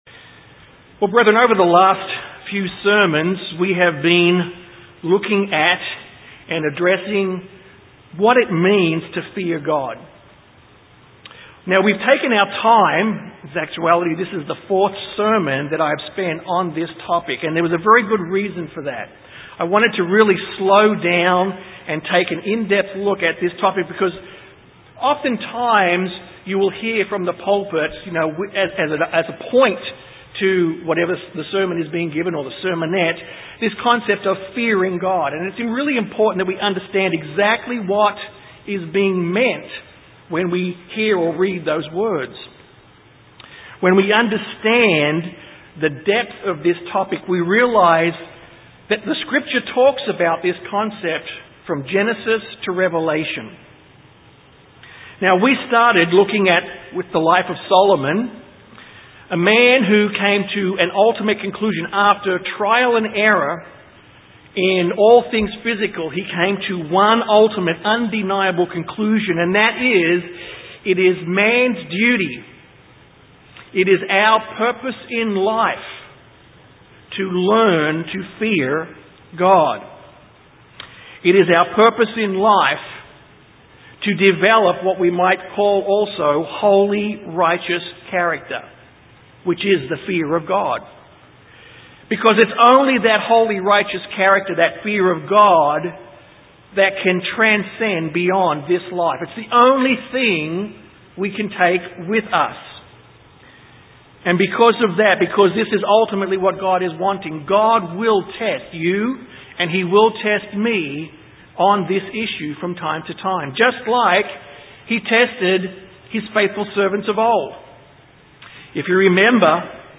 Given in San Antonio, TX
UCG Sermon Studying the bible?